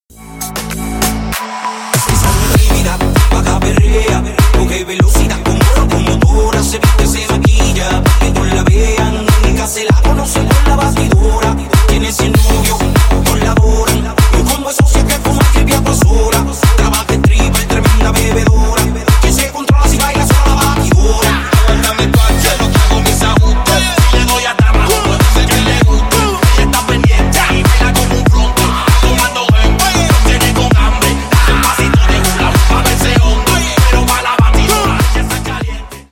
клубные , латинские
рэп